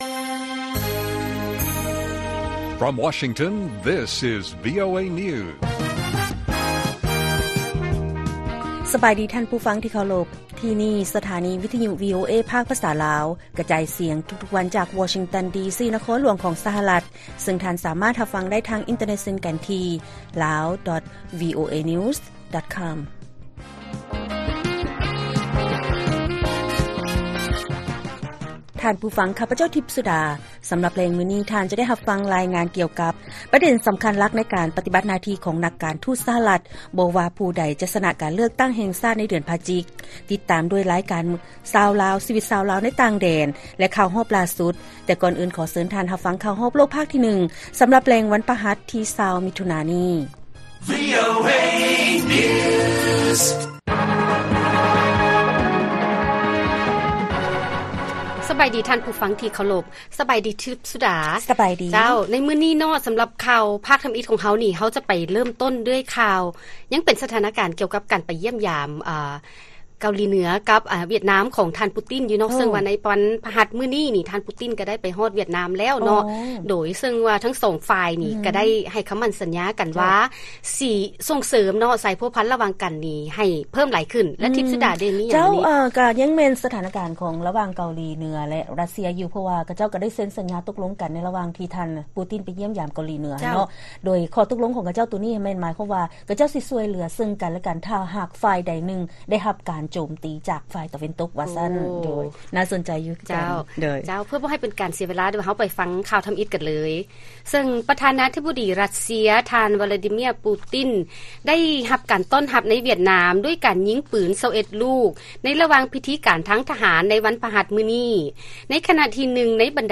ລາຍການກະຈາຍສຽງຂອງວີໂອເອລາວ: ຫວຽດນາມ ຕ້ອນຮັບ ຜູ້ນຳຣັດເຊຍ ທ່ານ ປູຕິນ ແລະ ໃຫ້ຄຳໝັ້ນສັນຍາວ່າ ຈະສົ່ງເສີມສາຍພົວພັນລະຫວ່າງກັນ ຂຶ້ນ